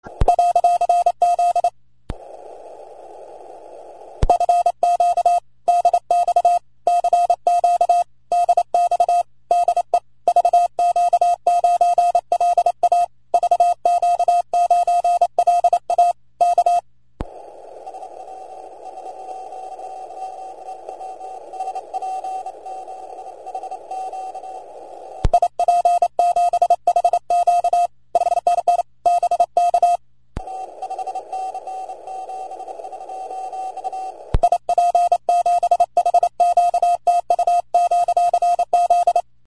(This is how a lot of signals Sound on VQ9 at the bottom of the cycle SFI 65)